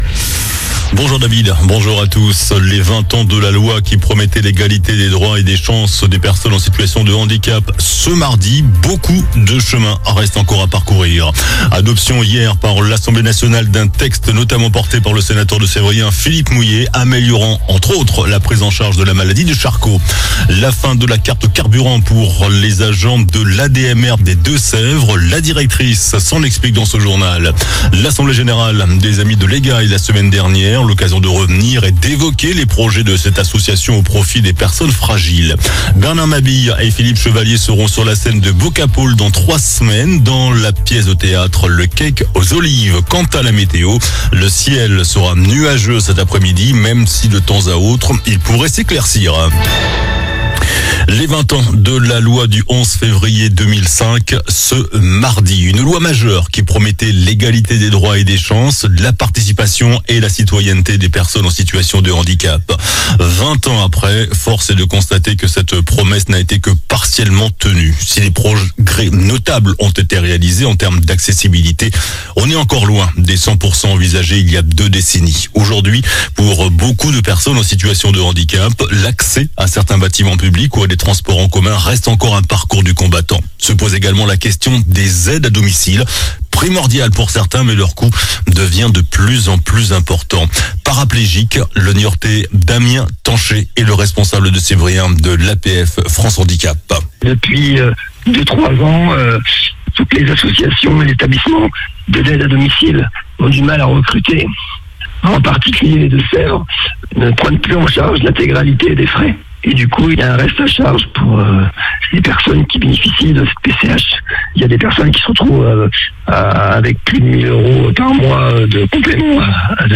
JOURNAL DU MARDI 11 FEVRIER ( MIDI )